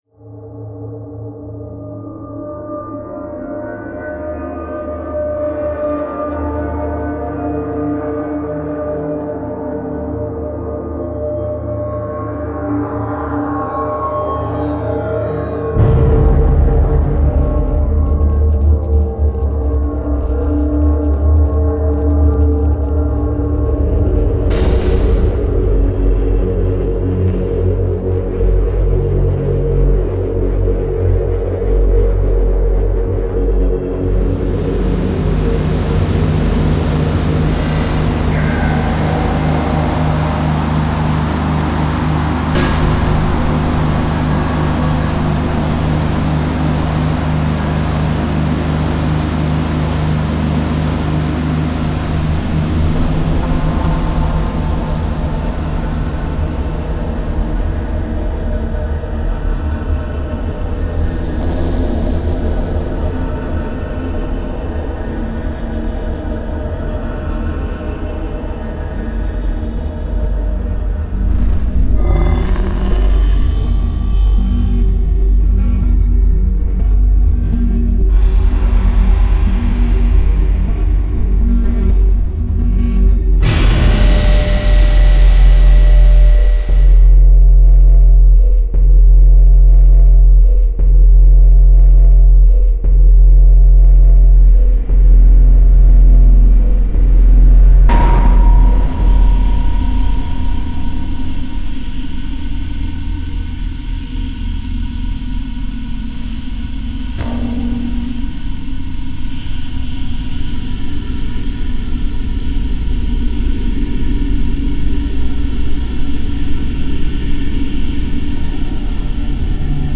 Cinematic Sound Effects Packs